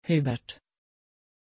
Aussprache von Hubert
pronunciation-hubert-sv.mp3